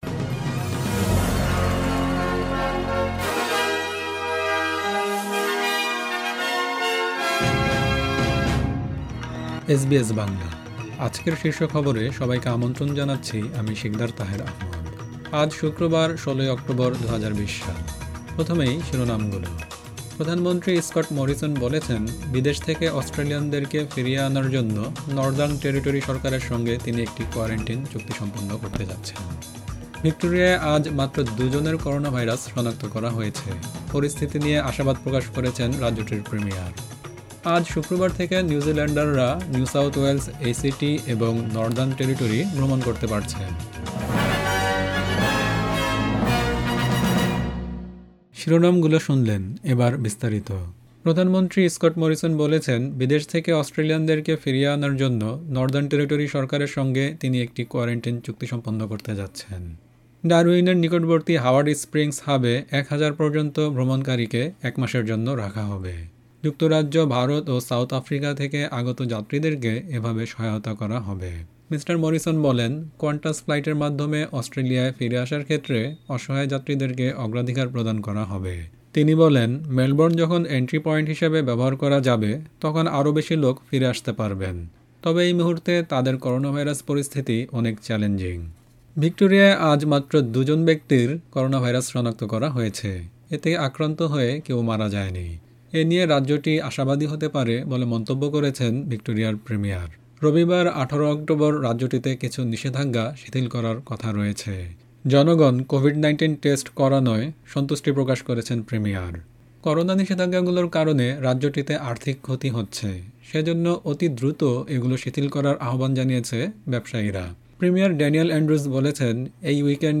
এসবিএস বাংলা শীর্ষ খবর: ১৬ অক্টোবর ২০২০